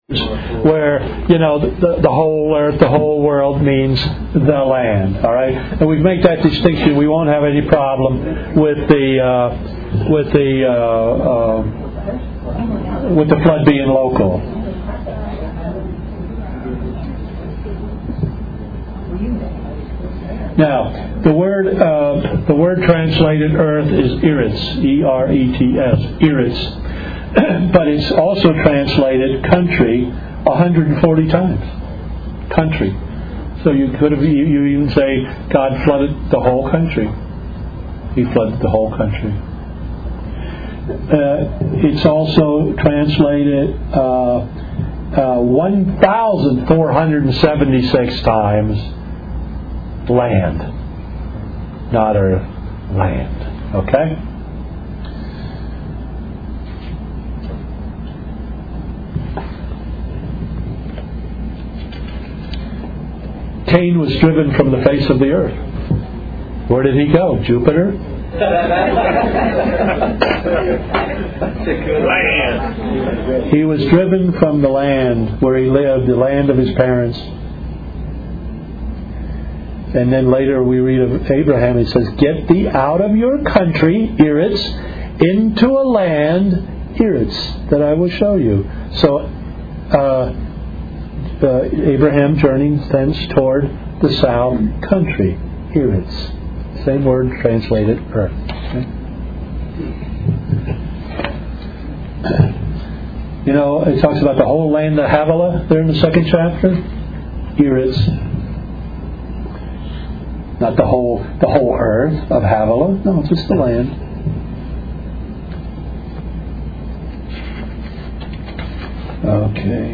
Nashville Conf 08 - WHERE DID GOD GET KNOWLEDGE? audio10 - video7